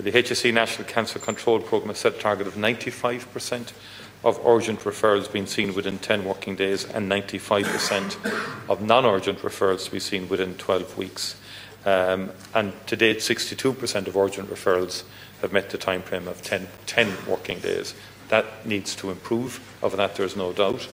Taoiseach Michael Martin says Government has provided significant investment towards cancer services……………